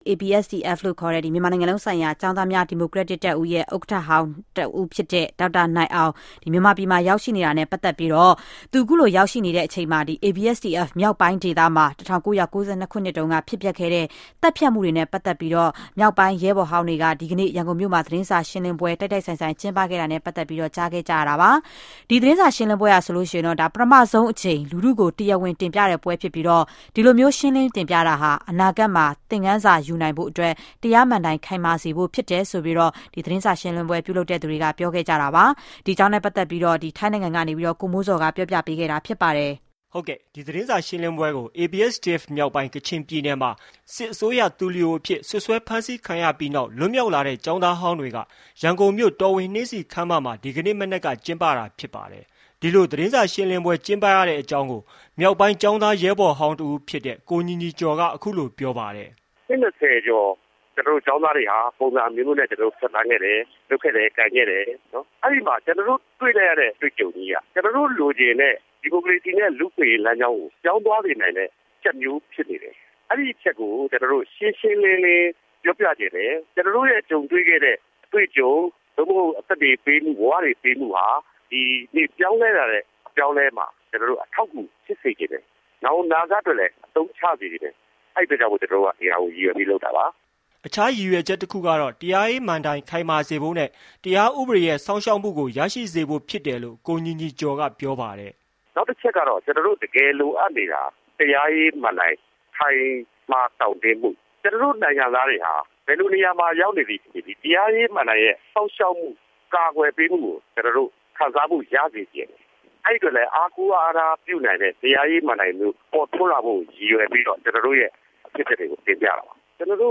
ABSDF Press Conference in YGN